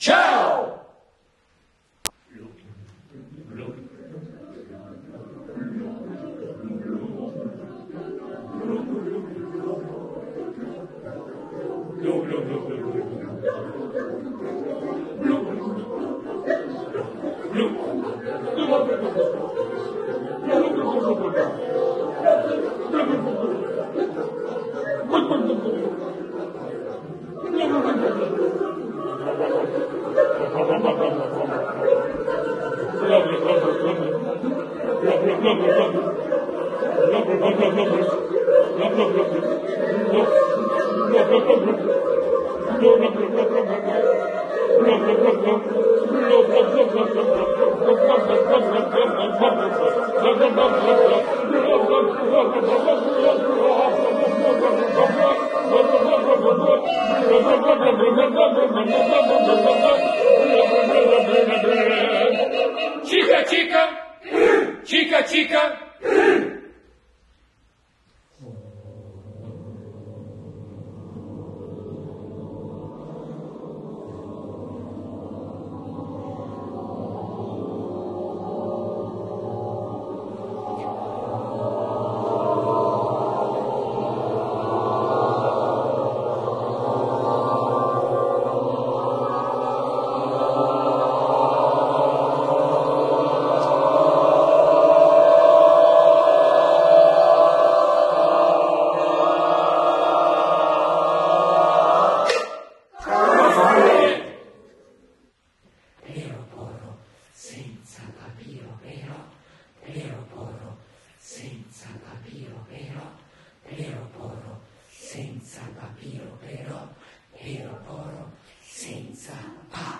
ESTRATTI DAI VARI CORSI E SEMINARI
REGISTRAZIONE DEL CORO FOLLE CON PERSONE IPOVEDENTI
È un lavoro di ricerca sulle possibilità timbriche della voce coordinate a impulsi corporei, al di là del linguaggio e del canto tradizionali.
Sviluppando una tecnica che permette di sentire a fondo le proprie capacità sonore inserite in un contesto di gruppo, passando da un suono ad un rumore, dal sussurro al grido, dalla sonorità cristallina e ondeggiante allʼespressione primitiva e percussiva.
coro-folle.m4a